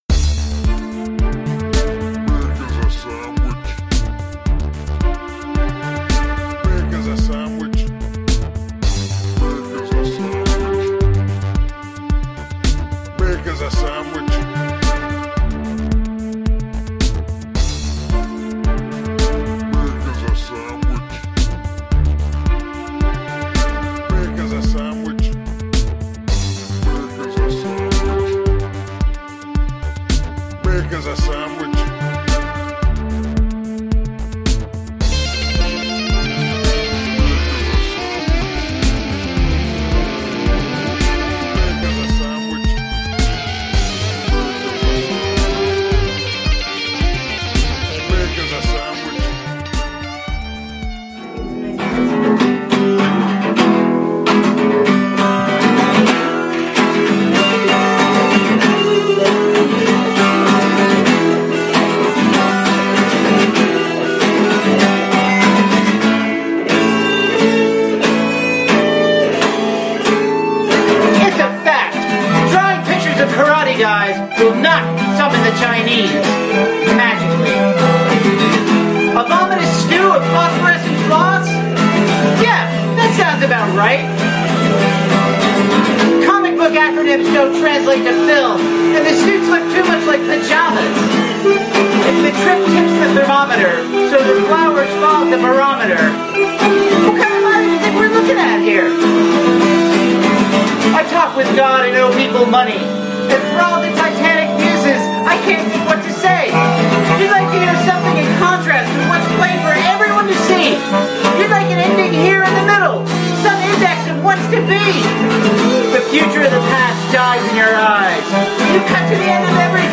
Melodica, Clarinet, Drum, Guitar, and Vocals
original songs and song-like originals